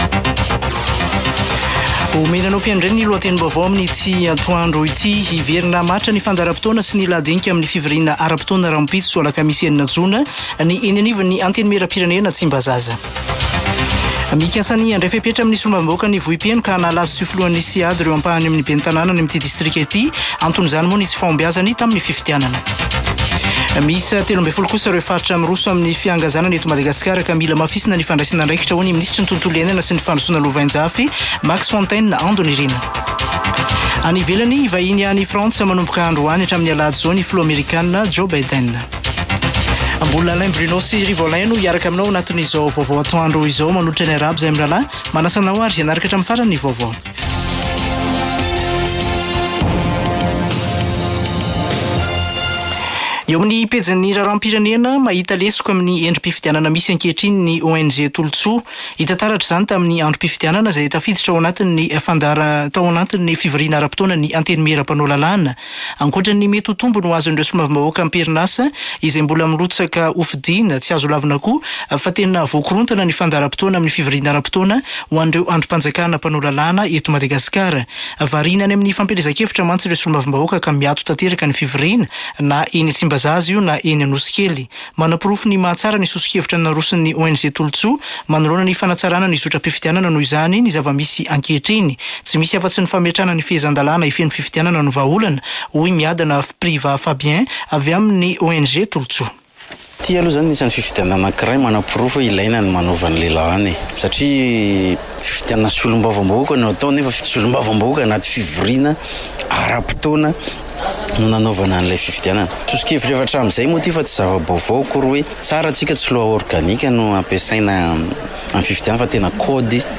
[Vaovao antoandro] Alarobia 05 jona 2024